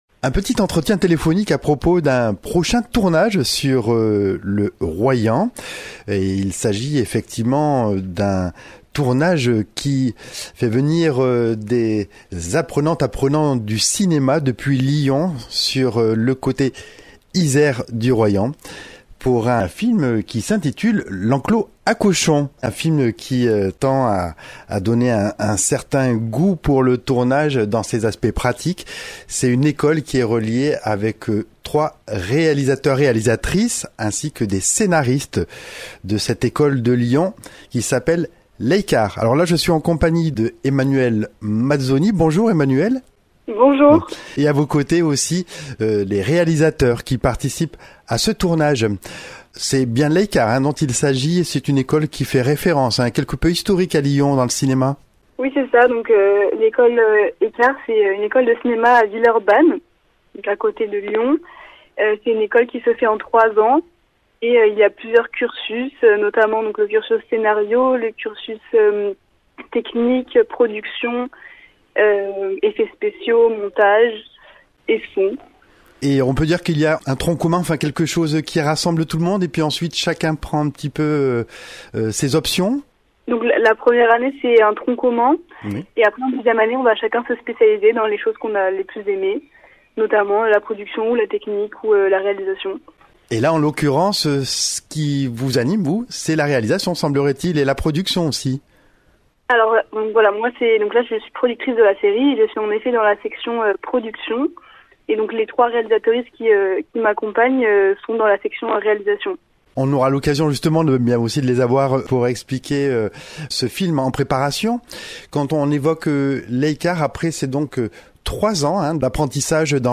Ils nous entretiennent à propos de ce film d’étude sous forme de mini série de 3 épisodes.